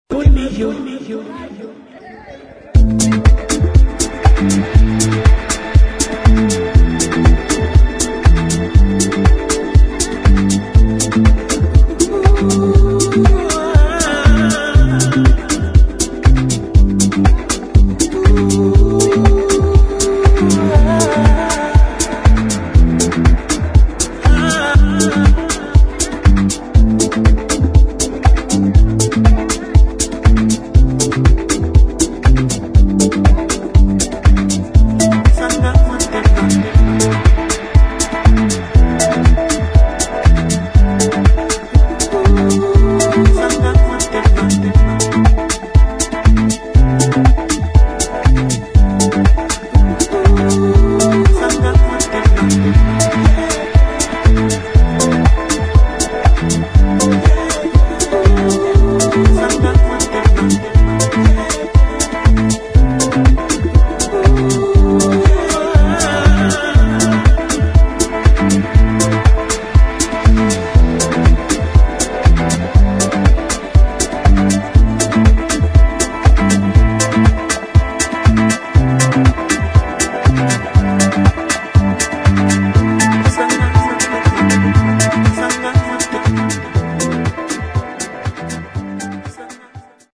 [ HOUSE / TECHNO ]
アウトドア用フロート・ハウス・ミュージック